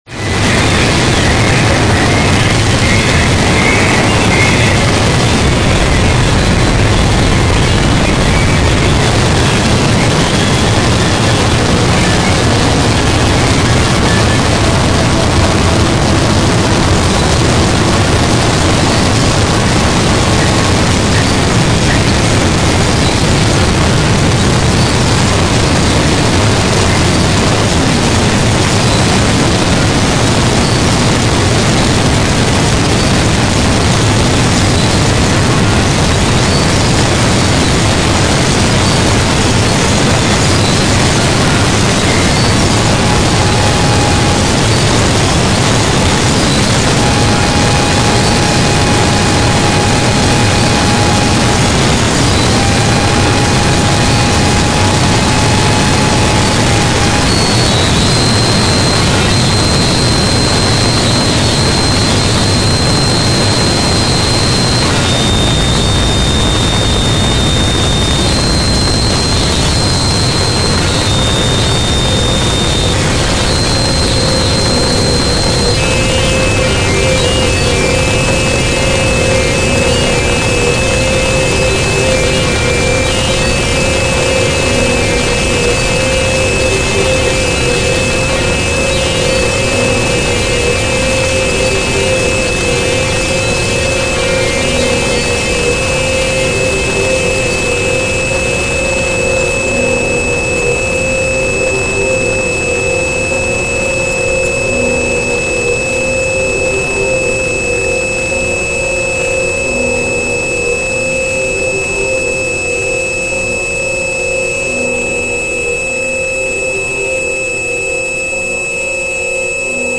wall noise
dark ambient/sinister guitar drone